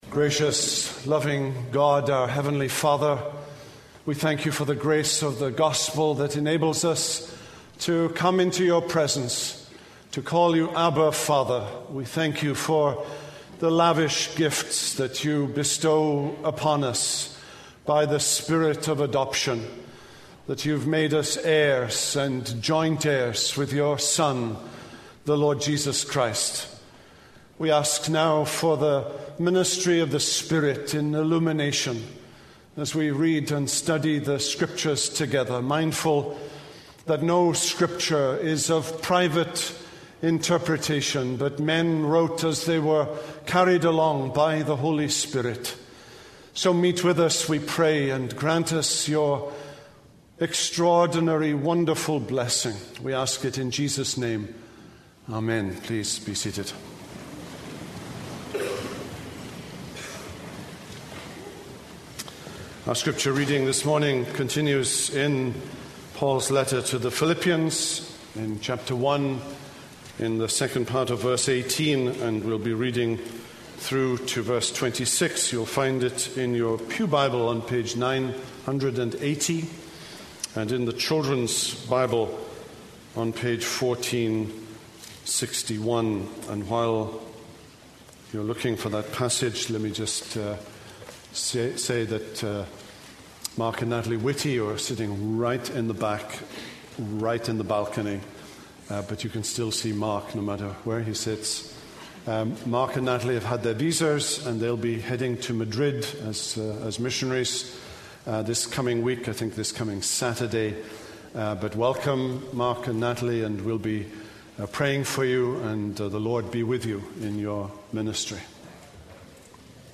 This is a sermon on Philippians 1:18b-26.